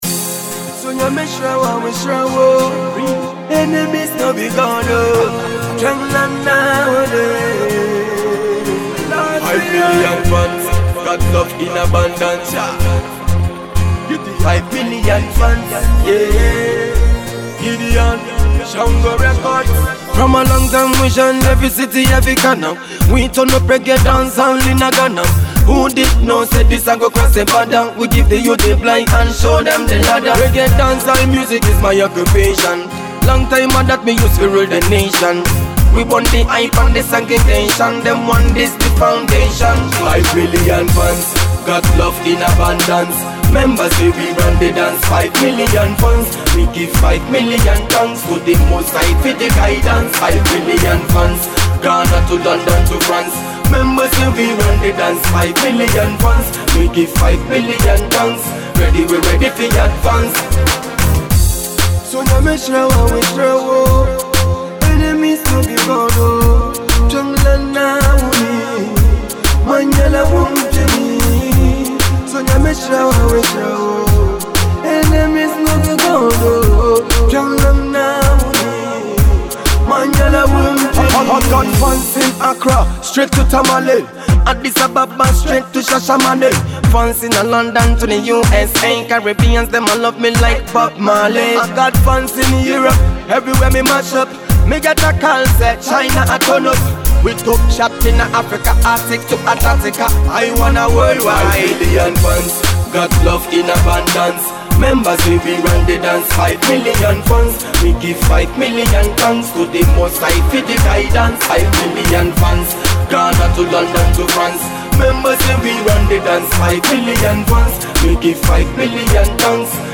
Real Reggae Dancehall Music for your Soul.